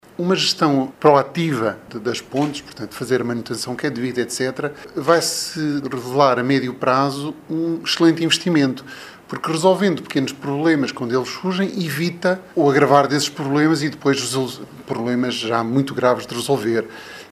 Estes números foram dados a conhecer na apresentação pública do relatório final do “Projeto Reconhecer”, um estudo encomendado, em 2019, pelo Município barcelense e que envolveu a Universidade Lusófona de Humanidades e Tecnologias e o Laboratório Nacional de Engenharia Civil.